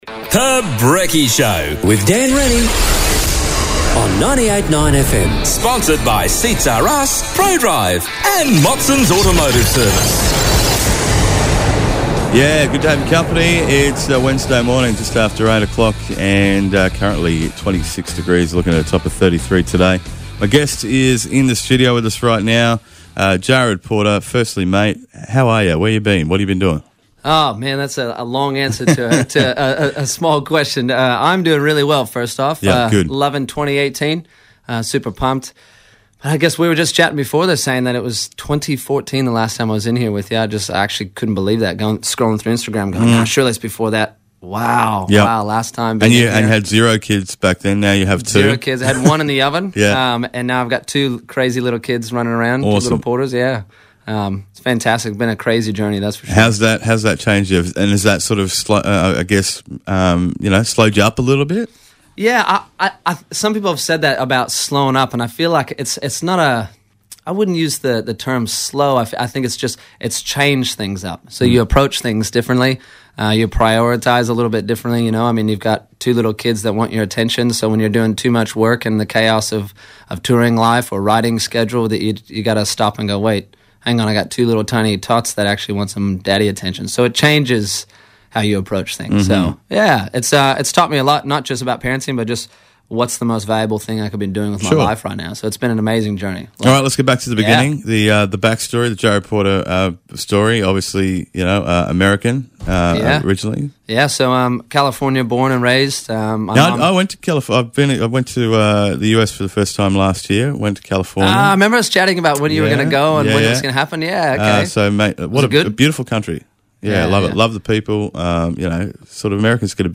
dropped into the studio this morning having a yarn